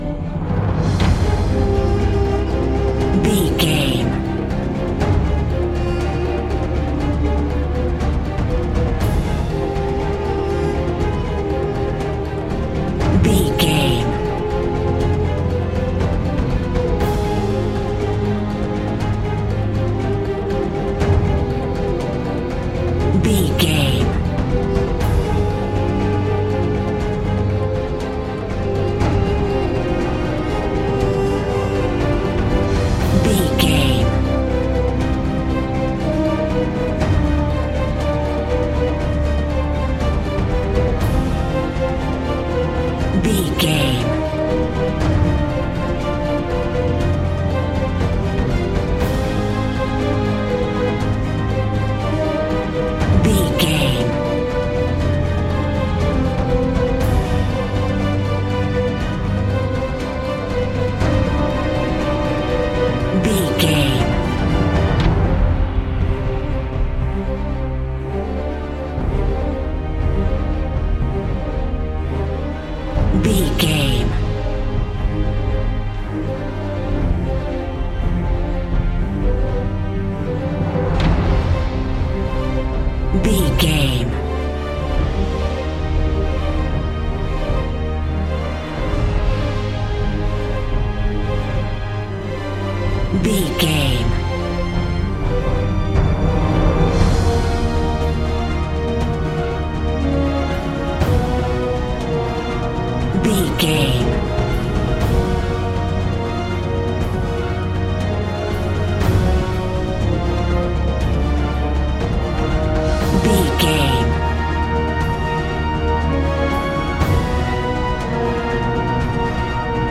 Epic / Action
Fast paced
In-crescendo
Aeolian/Minor
strings
drums
orchestral hybrid
dubstep
aggressive
energetic
intense
powerful
bass
synth effects
wobbles
driving drum beat